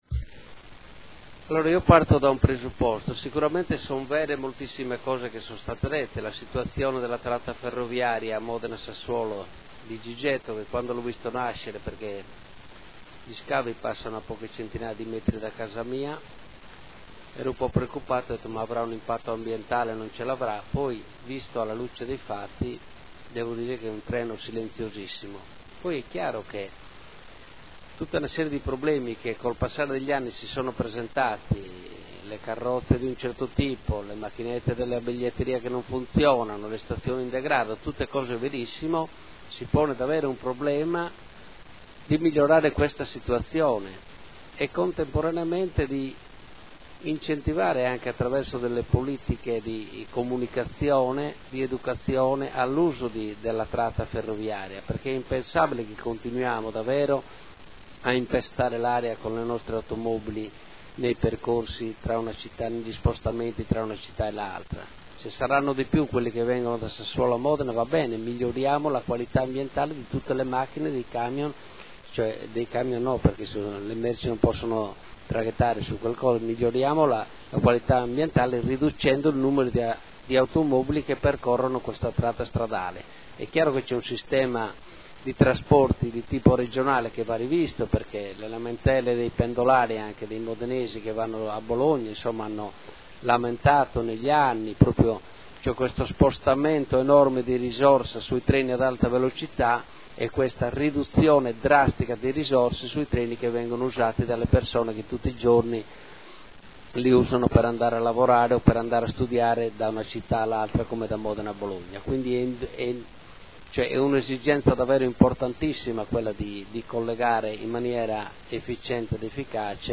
Marco Cugusi — Sito Audio Consiglio Comunale